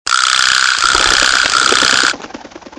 Title=crecelle_01
B_CRECELLE.mp3